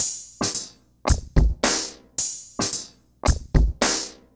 RemixedDrums_110BPM_07.wav